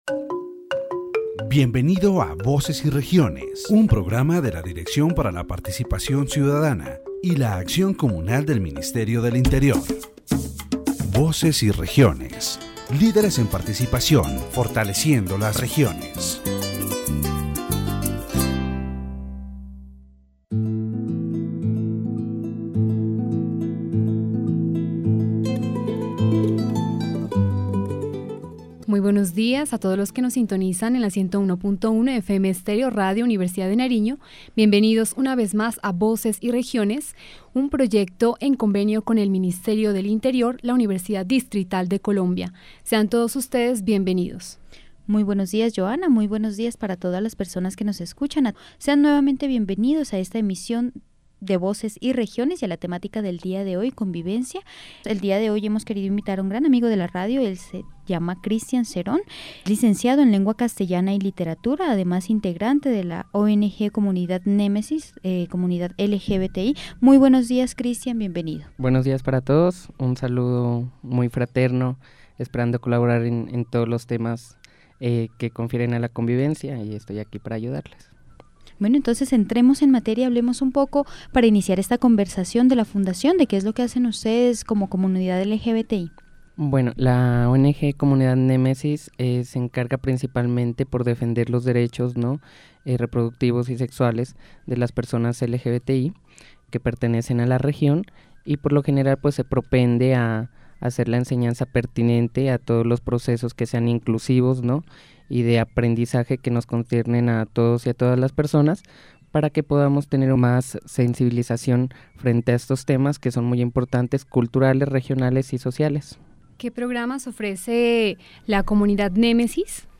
The radio program Voces y Regiones organized a conversation about the challenges and triumphs of the LGBTQ+ community in Colombia.